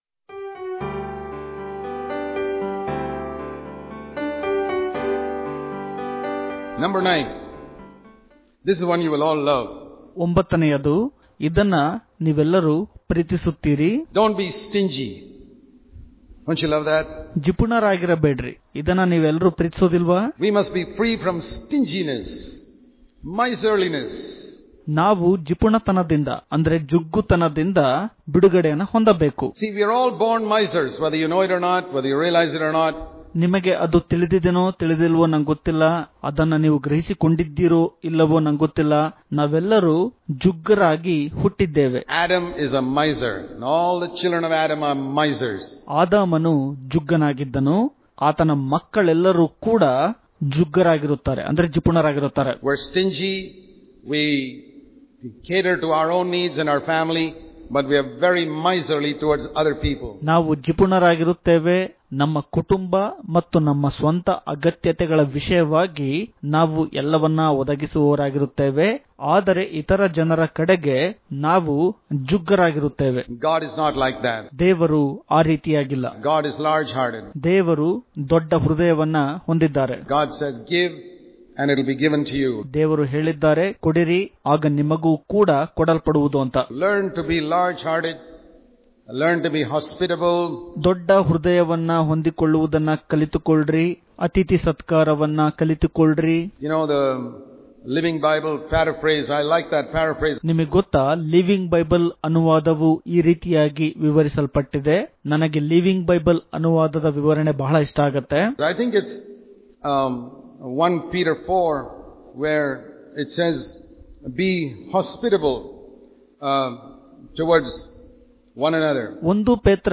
October 10 | Kannada Daily Devotion | Freedom From Stinginess Daily Devotions